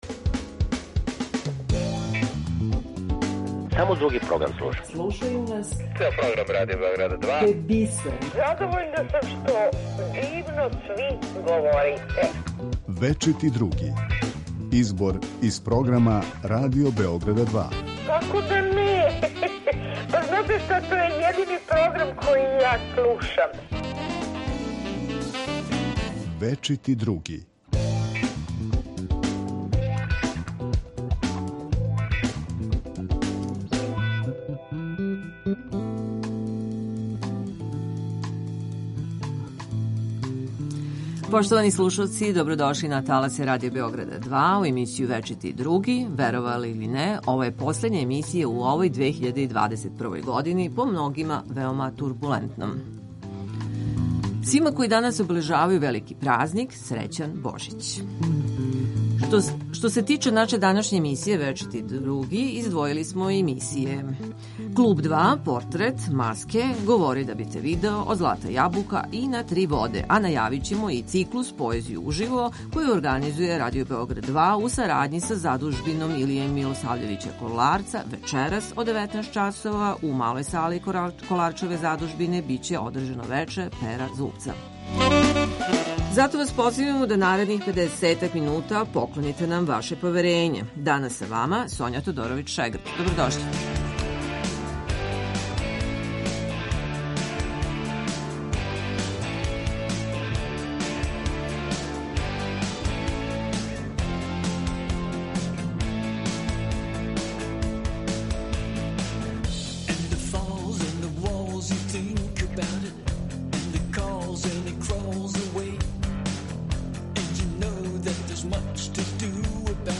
џез музичара Војислава Бубишу Симића и градоначелнике четири града - Београда, Ниша, Бања Луке и Новог Сада.